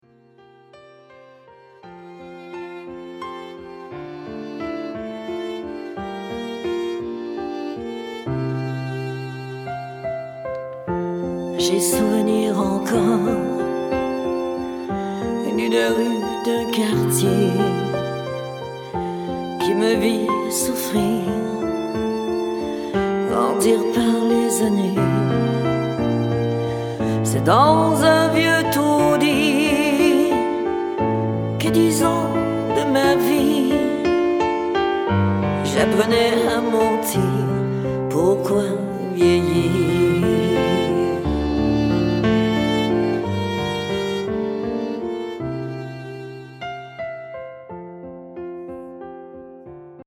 chanteuse & choriste
(cover)